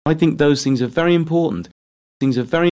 In England, this frequently weakens to no more than a little ə, just like the indefinite article a. (Other accents, including General American, can weaken are without losing its r sound.) Here are native utterances by British speakers in which are is merely a schwa: